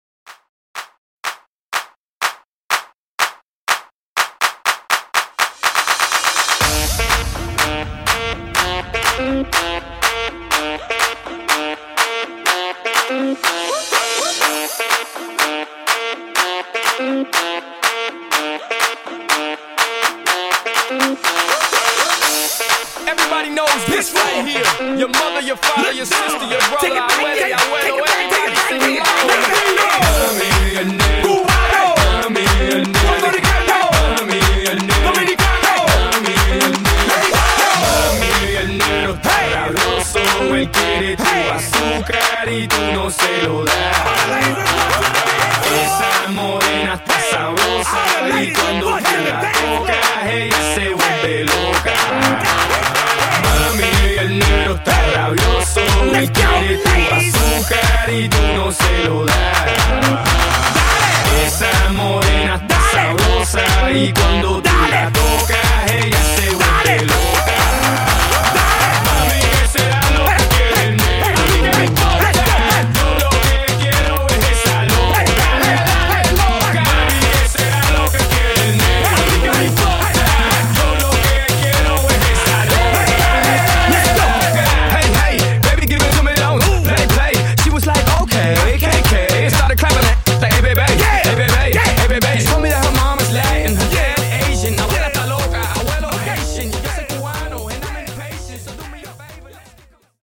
Clap Intro Mixshow)Date Added